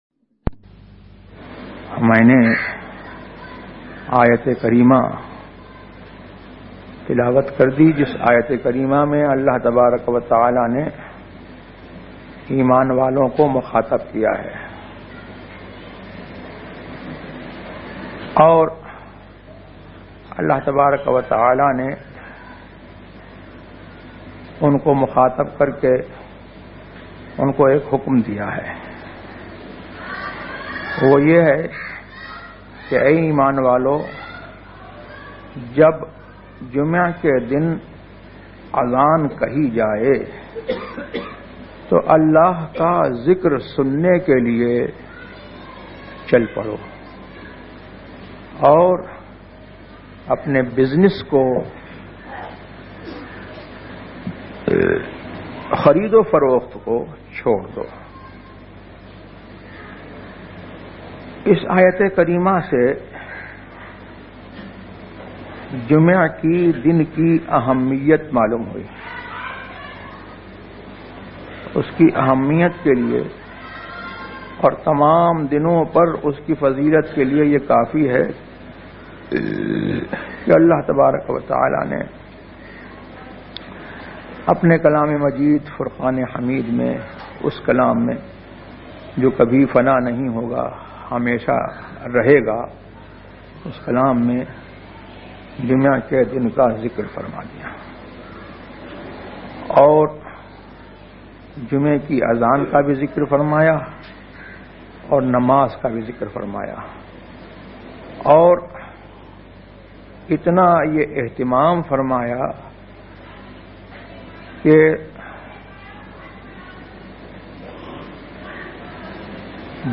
تقاریر آواز تاج الشریعہ مفتی اختر رضا خان ازہری